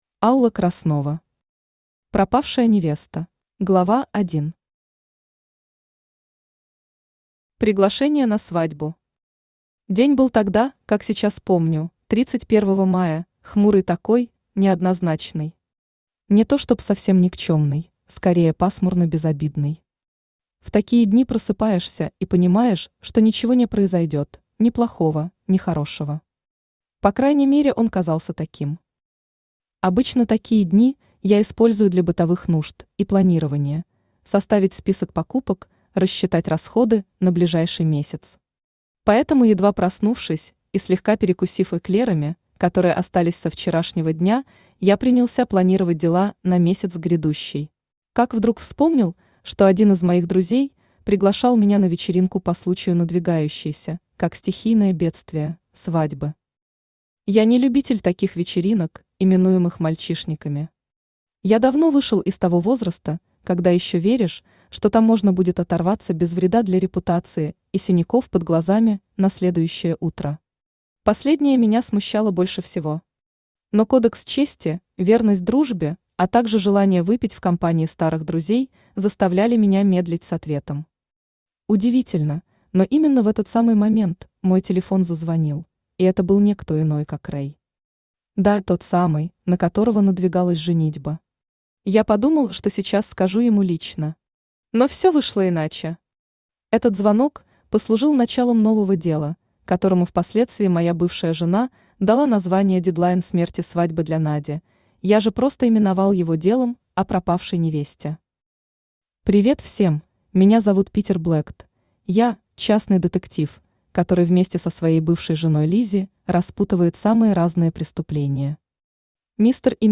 Читает: Авточтец ЛитРес
Аудиокнига «Пропавшая невеста».